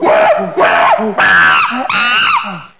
chimpanze.mp3